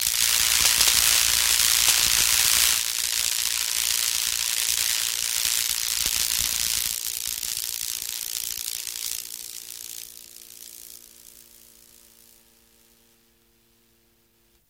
Подборка включает разные варианты звучания, от глухих разрядов до шипящих импульсов.
Звук разряда шаровой молнии